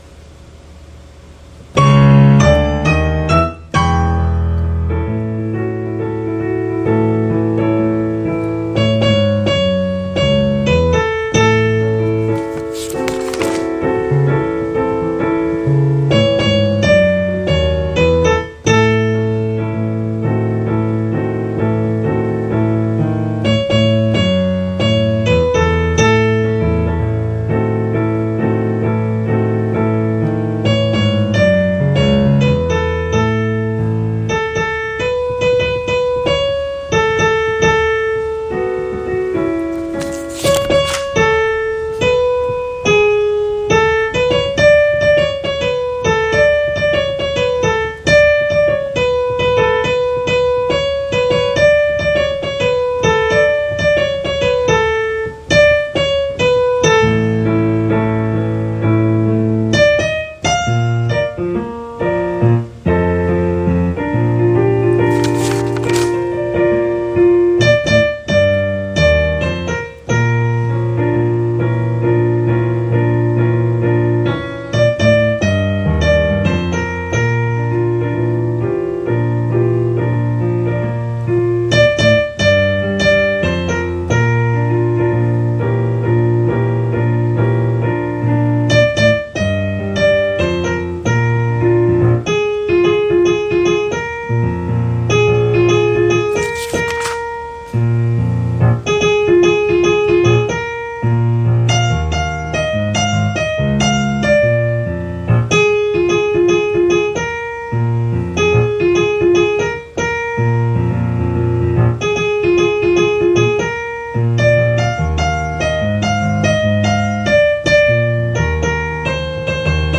Soprano 1Download Soprano 1 Rehearsal Track
2-Still-I-Rise-Soprano-1.mp3